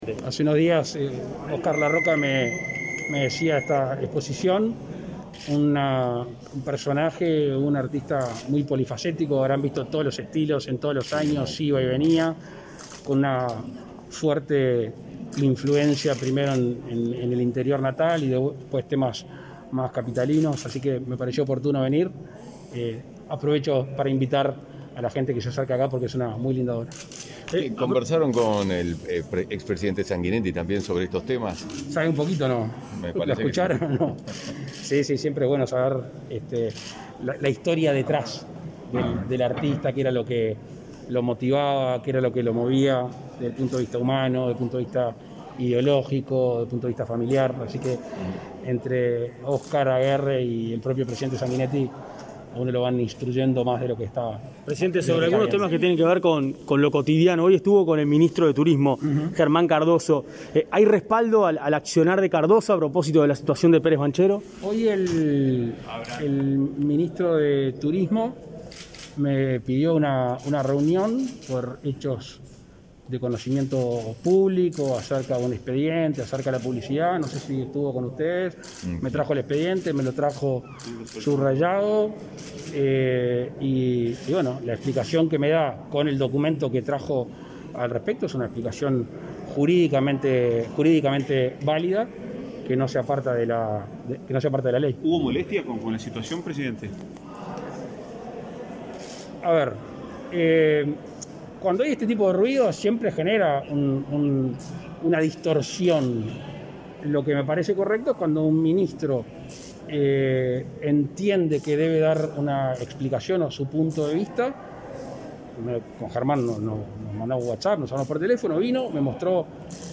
Declaraciones a la prensa del presidente de la República, Luis Lacalle Pou
Al finalizar su recorrido, el mandatario brindó declaraciones a los medios de comunicación presentes.